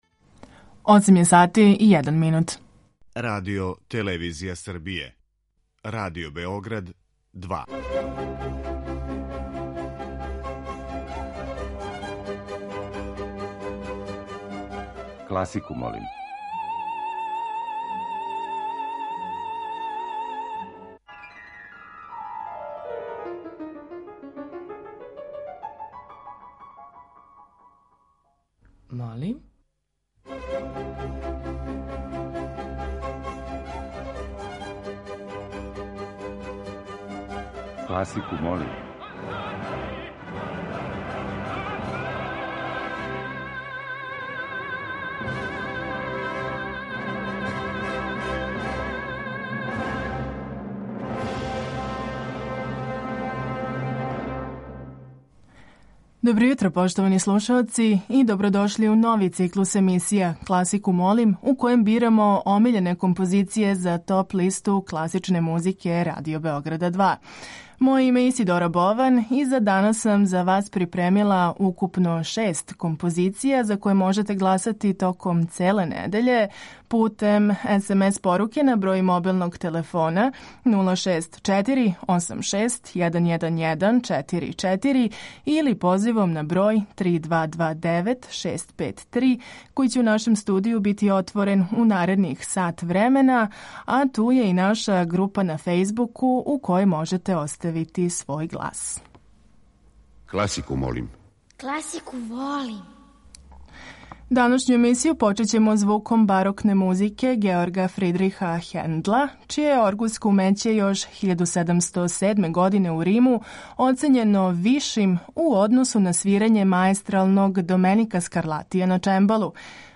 Тема циклуса ове недеље је филмска музика.